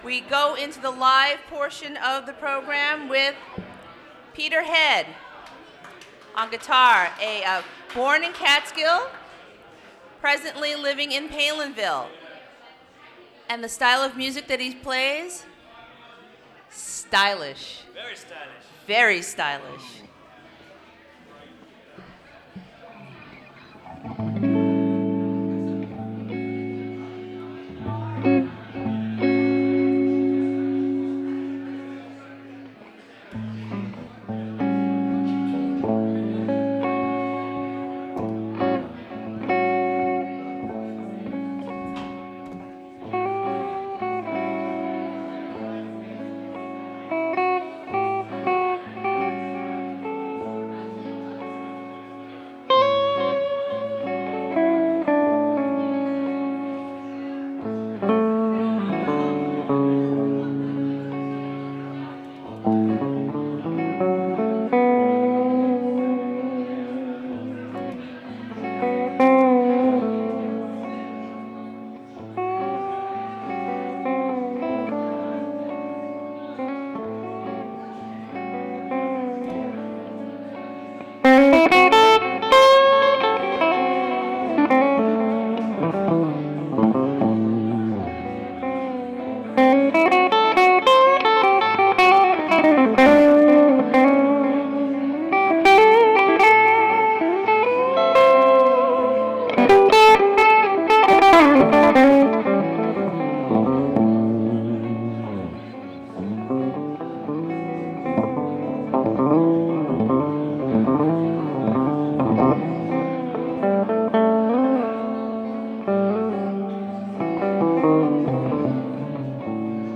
Open Mic at Crossroads Brewing
Recorded from WGXC 90.7-FM webstream.